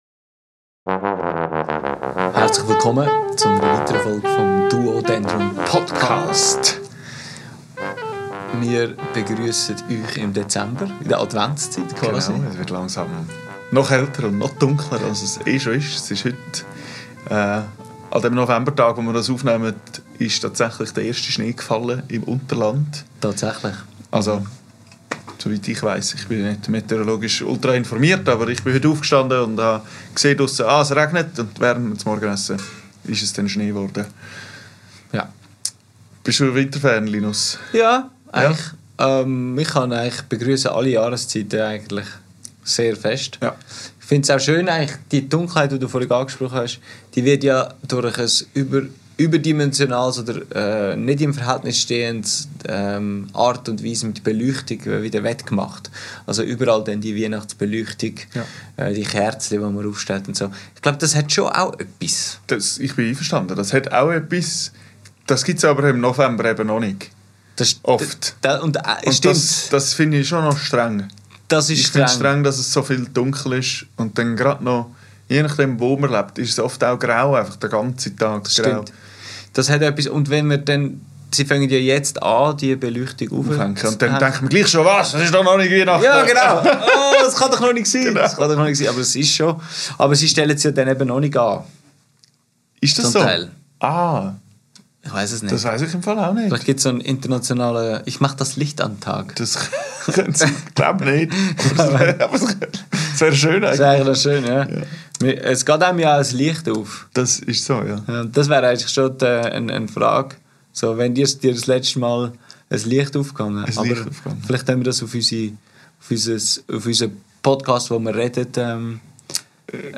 Aufgenommen am 20.11.2024 im Atelier